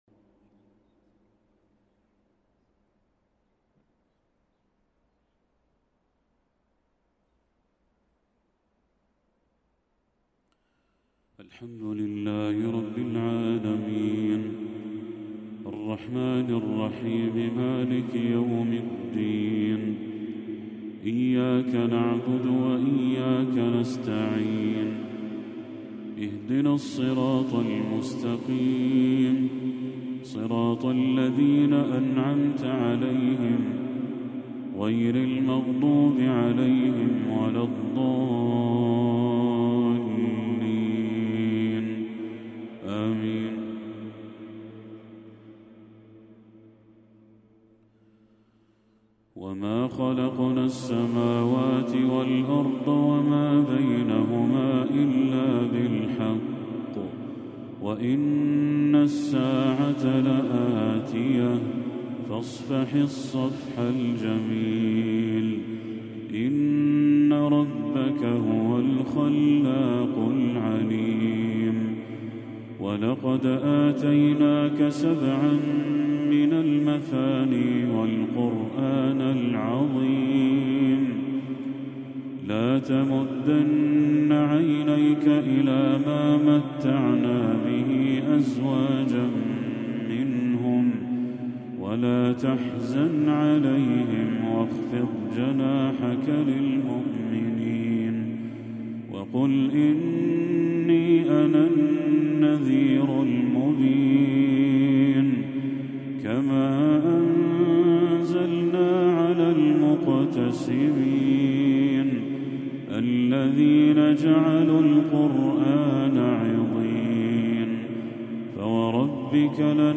تلاوة شجية لخواتيم سورتي الحجر والنحل